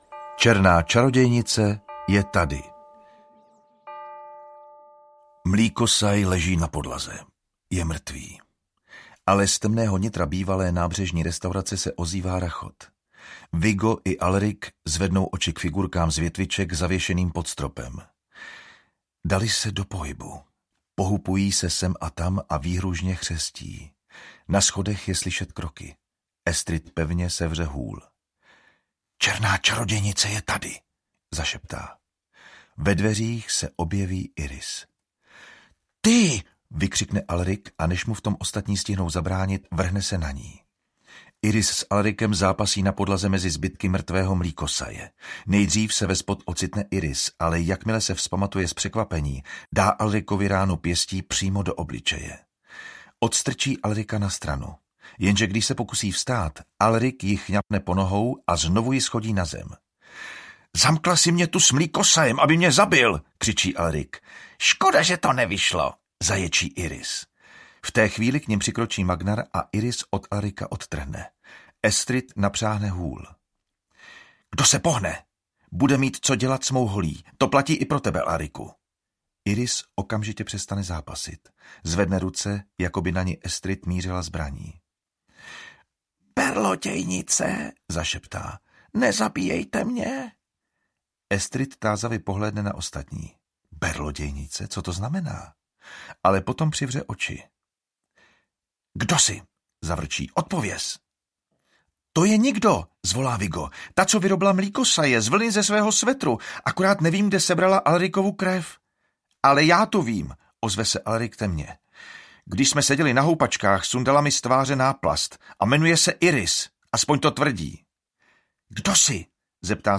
Audio knihaPAX 5: Sluhové zla
Ukázka z knihy
• InterpretJan Vondráček